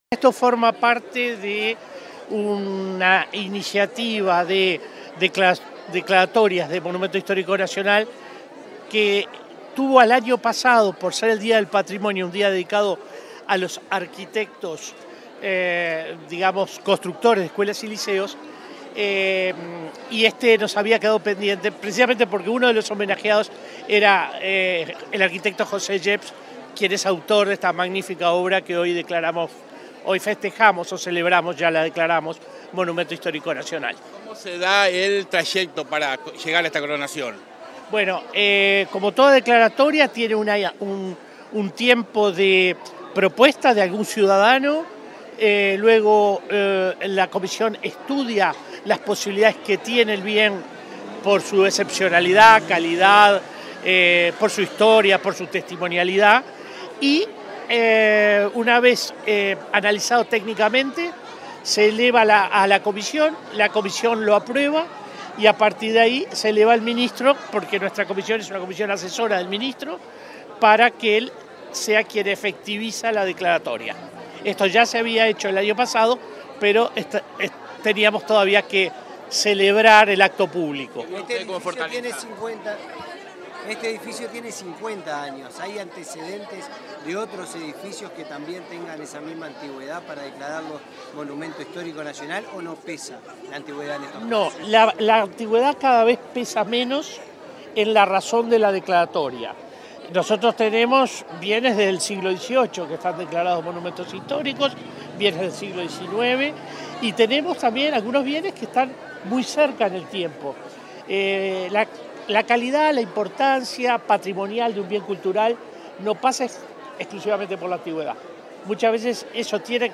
Declaraciones del titular de la Comisión del Patrimonio Cultural, William Rey 11/04/2024 Compartir Facebook Twitter Copiar enlace WhatsApp LinkedIn Tras la declaratoria del liceo n.°1 de Salto como Monumento Histórico Nacional, este 11 de abril, el titular de la Comisión del Patrimonio Cultural de la Nación, William Rey, realizó declaraciones a la prensa.